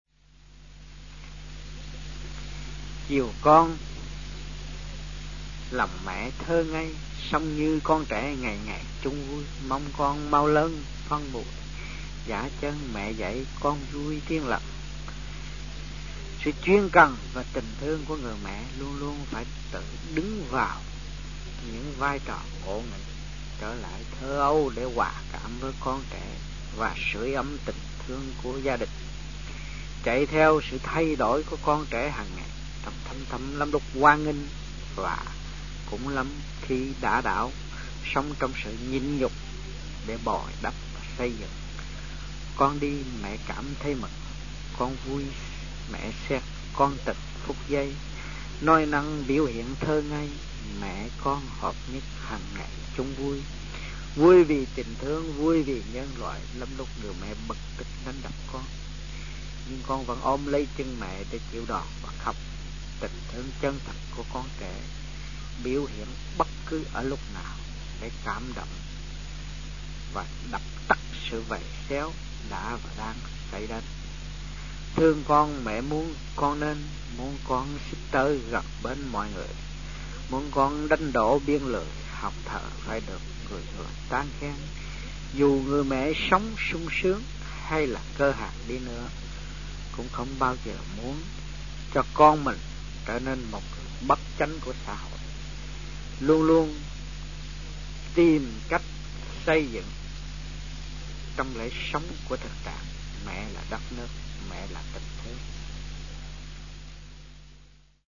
Địa danh : Sài Gòn, Việt Nam
Trong dịp : Sinh hoạt thiền đường